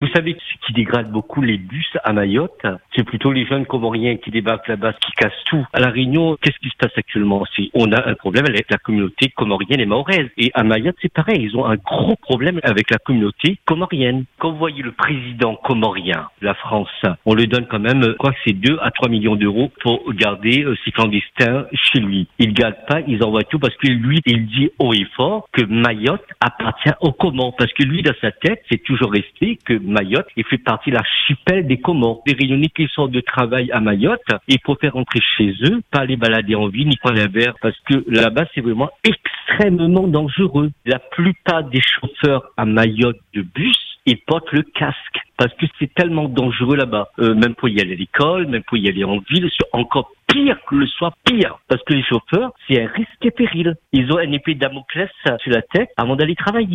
Écoutez le témoignage de cet ancien chauffeur, qui raconte ces journées sous tension.